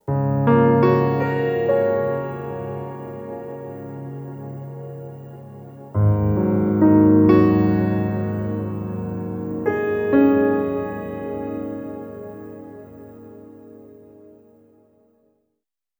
Treated Piano 01.wav